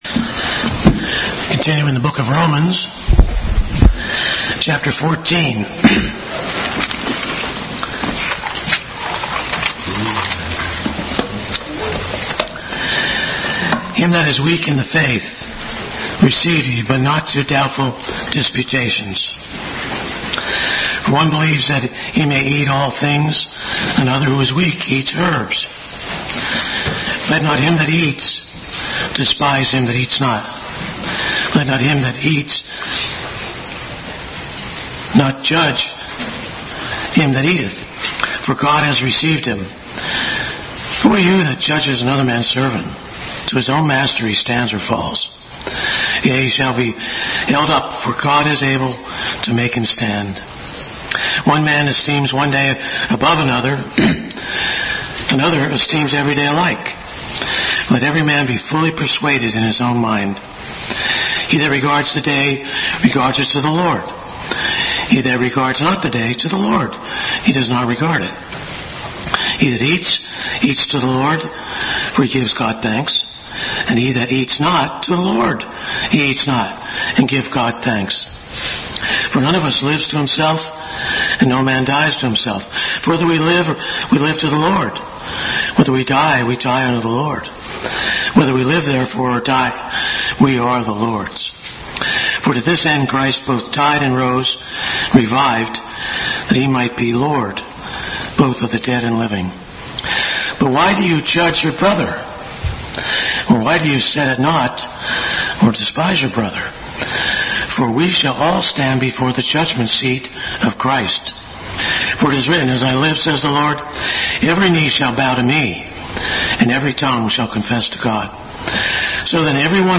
Romans 14:1-23 Service Type: Sunday School Bible Text